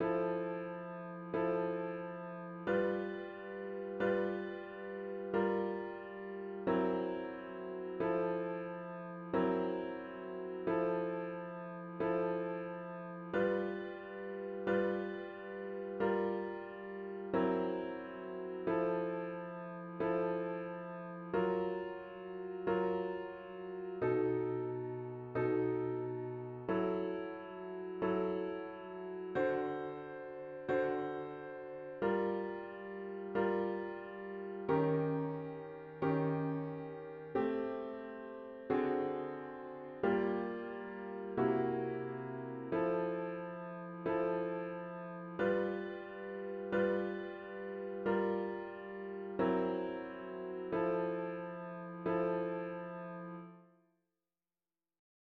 An audio of the chords only version is